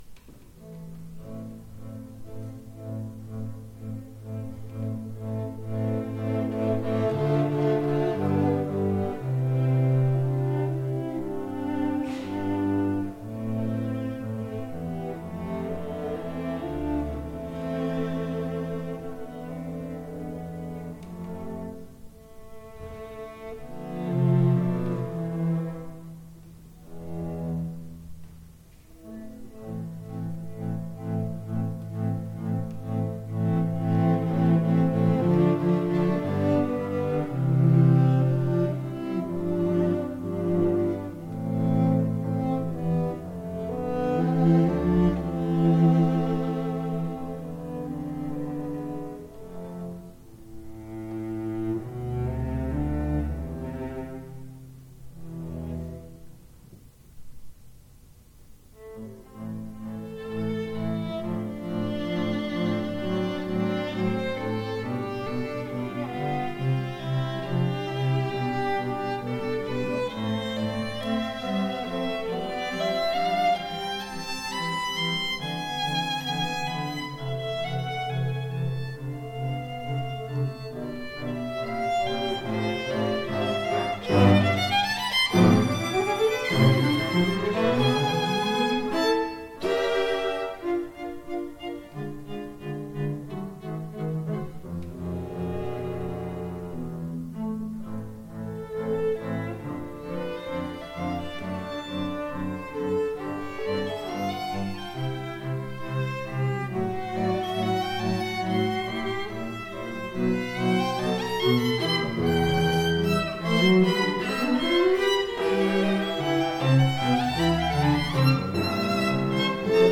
the students
Chamber, Choral & Orchestral Music
Chamber Groups